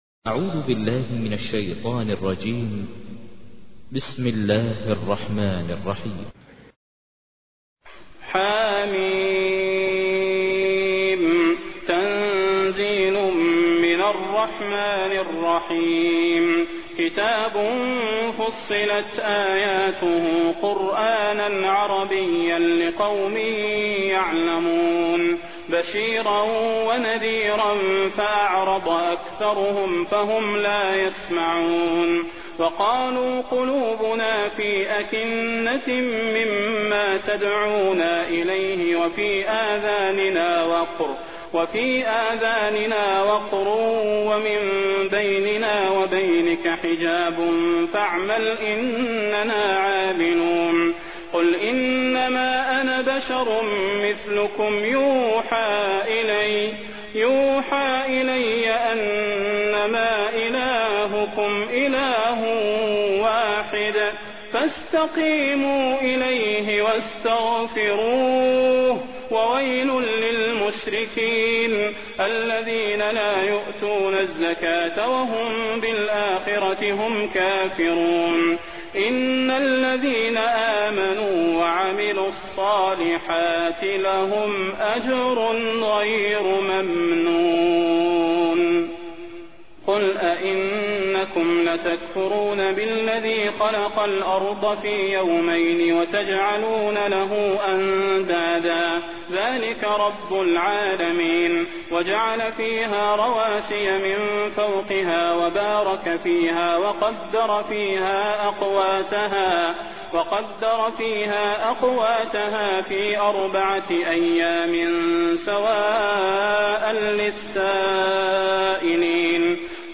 تحميل : 41. سورة فصلت / القارئ ماهر المعيقلي / القرآن الكريم / موقع يا حسين